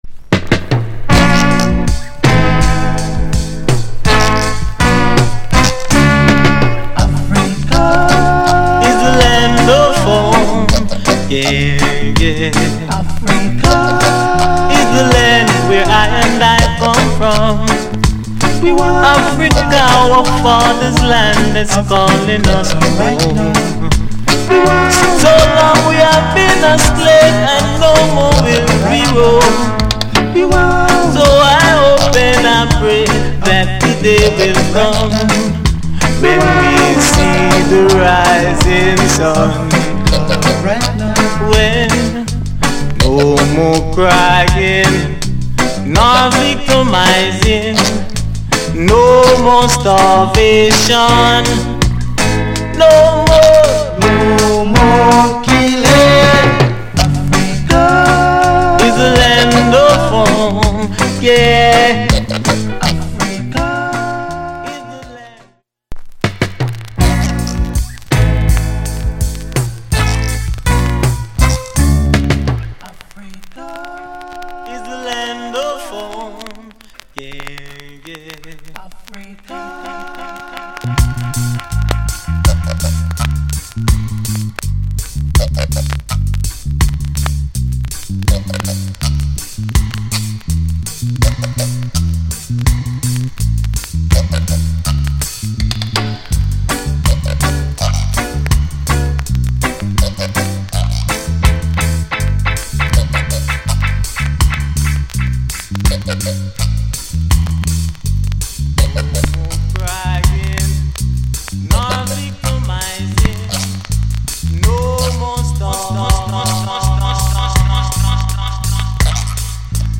* ゆったりとした広さを感じるようなリズムの乗せて歌う、アフリカの歌。